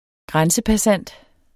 Udtale [ ˈgʁansəpaˌsanˀd ]